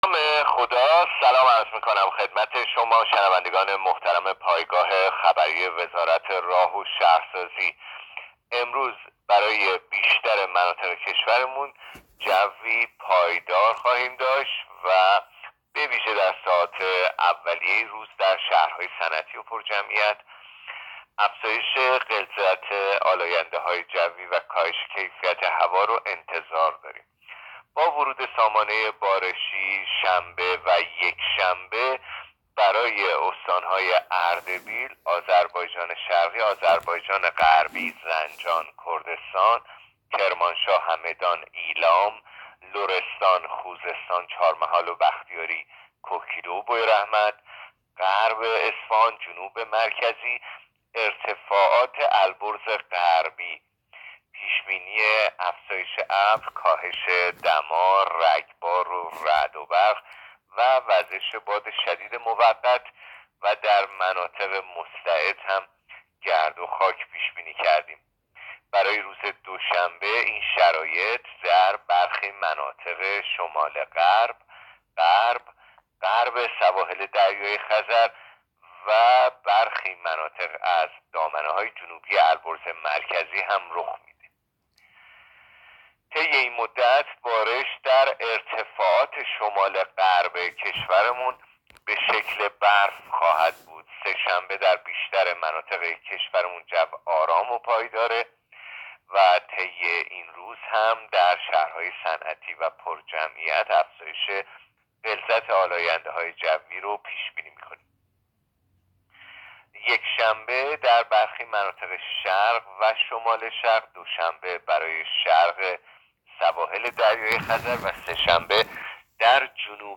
گزارش رادیو اینترنتی پایگاه‌ خبری از آخرین وضعیت آب‌وهوای ۲۳ آبان؛